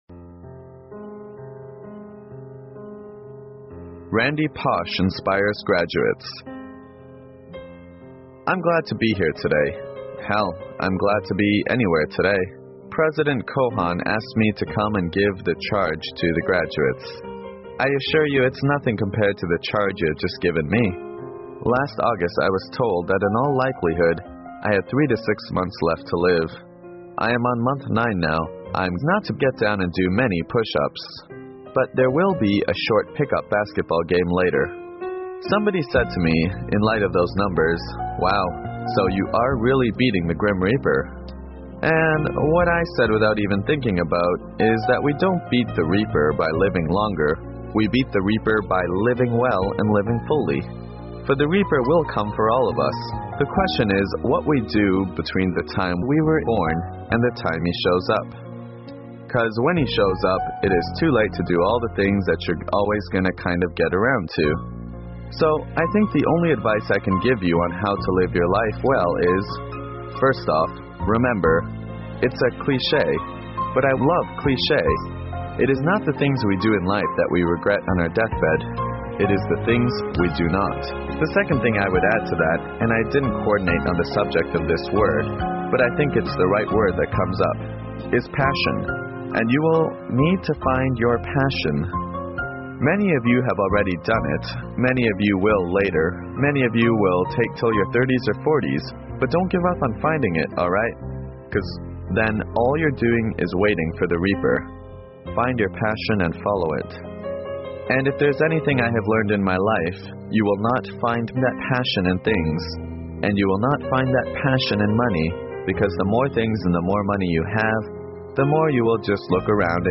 精美英文欣赏:兰迪教授对毕业生的激励演讲 听力文件下载—在线英语听力室